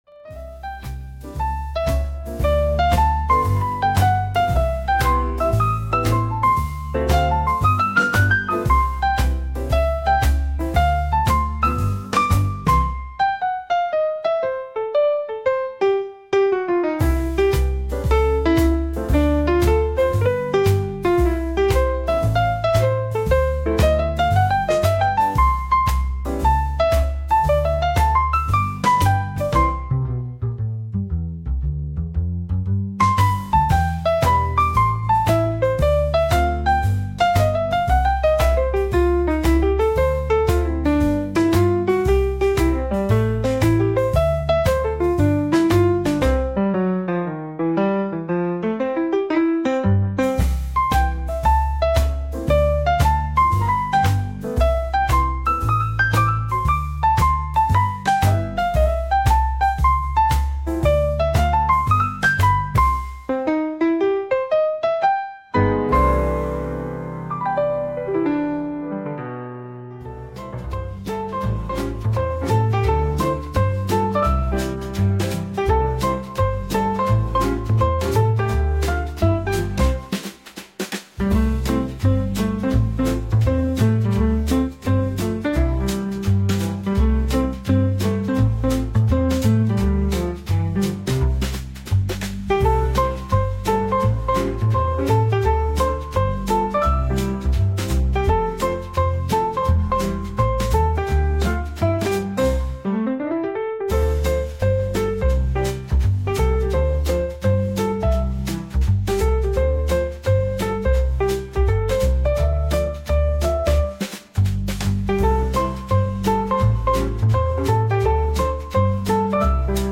こちらがレコード音質加工前の原音です
Lo-Fi jazz